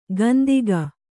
♪ gandiga